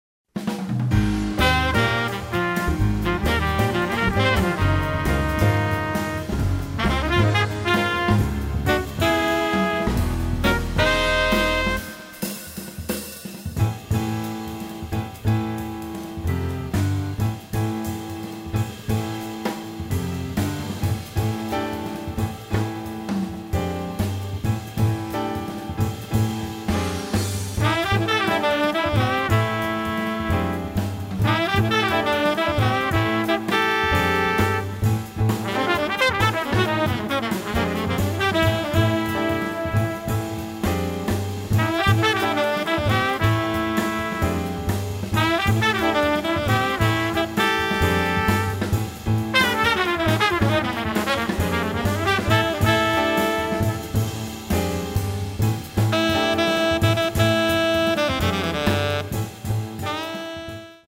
trumpet, keyboards